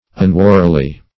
Search Result for " unwarily" : Wordnet 3.0 ADVERB (1) 1. without heed or caution ; The Collaborative International Dictionary of English v.0.48: Unwarily \Un*wa"ri*ly\, adv.
unwarily.mp3